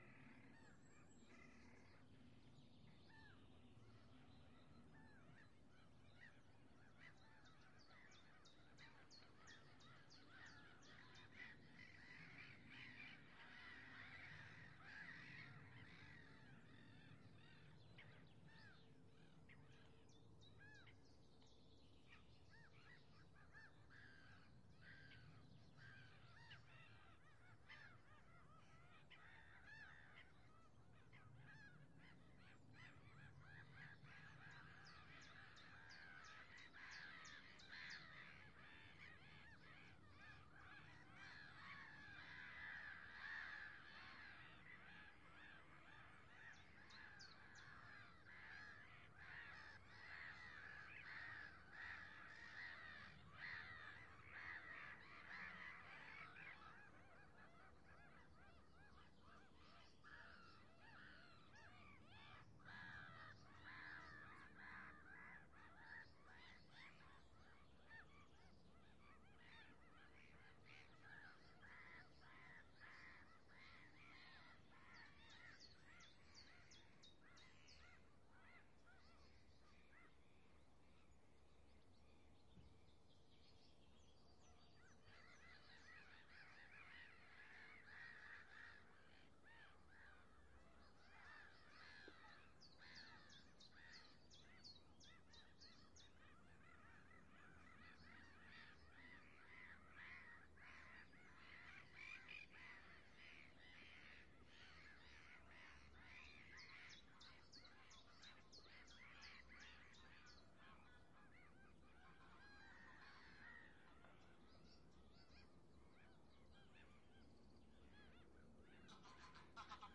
描述：一个短暂的编辑记录鸟鸣从我的花园里的船体蓝色和伟大的山雀一些雀黑鸟等
Tag: 鸟鸣声 自然 春天 F 现场录音